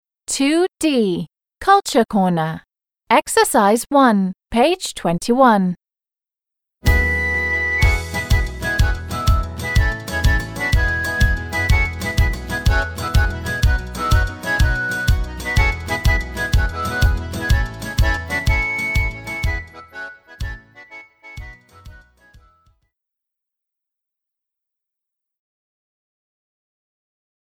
The music reminds me of Ireland.